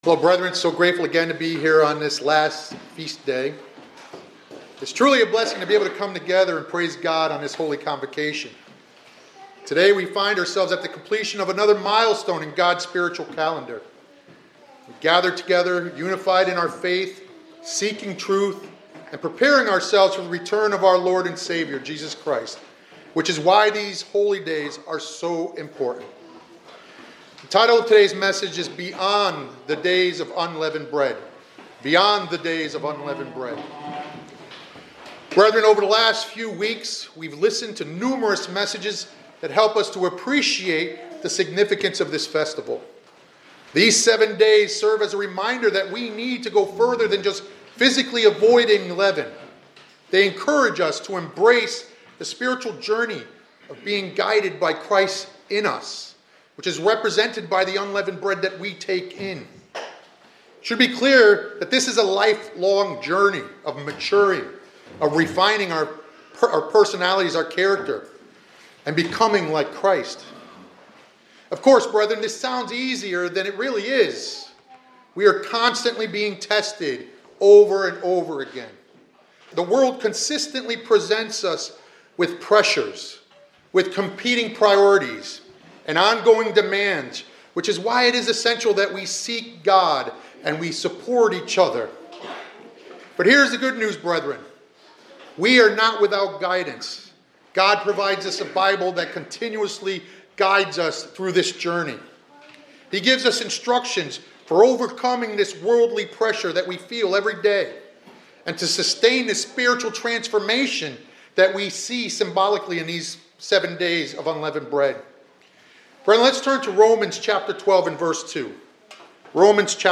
This sermonette emphasizes that the Days of Unleavened Bread are not just about temporarily avoiding physical leaven, but about committing to a lifelong spiritual transformation. It calls believers to continue resisting worldly influences, renewing their minds, and actively living God’s will after the Holy Days end.